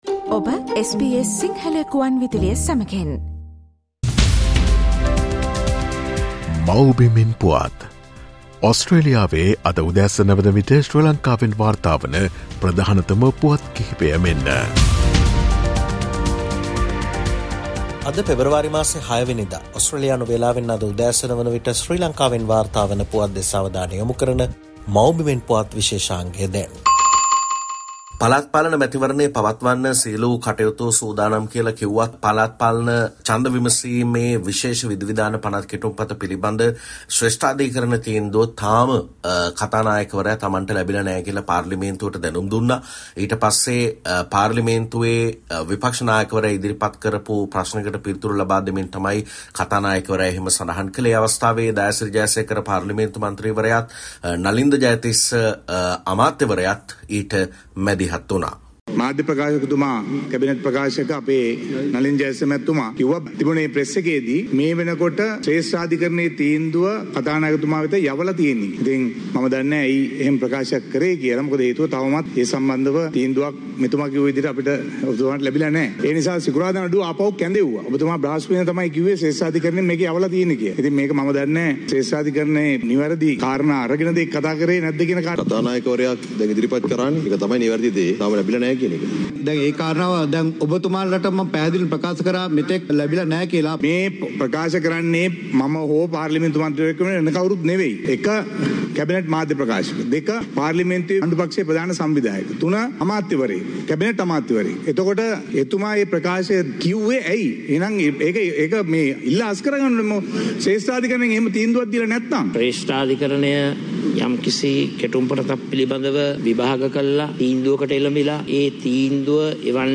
SBS Sinhala featuring the latest news reported from Sri Lanka - Mawbimen Puwath Share